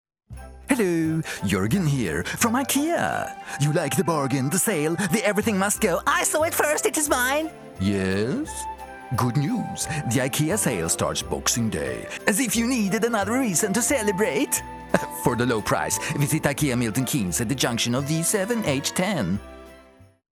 SWEDISH. Presenter & Actor. From deadly, deep and serious to comedy spoof and seduction!